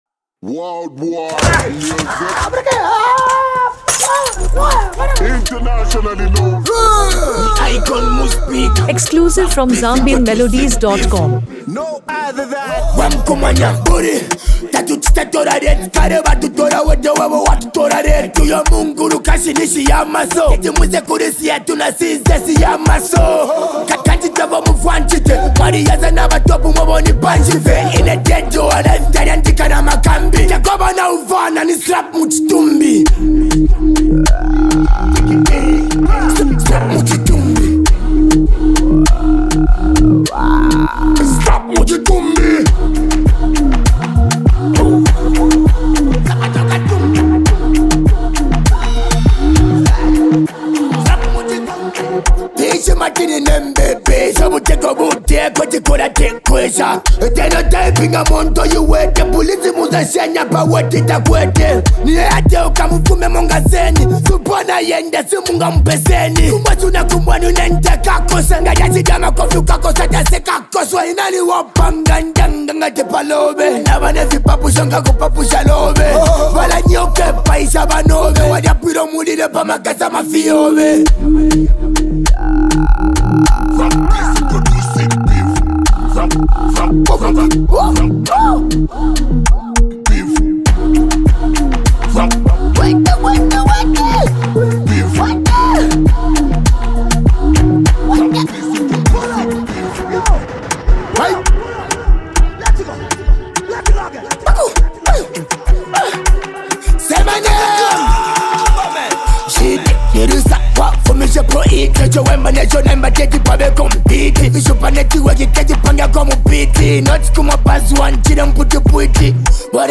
A Hard-Hitting Diss Track to Slap Dee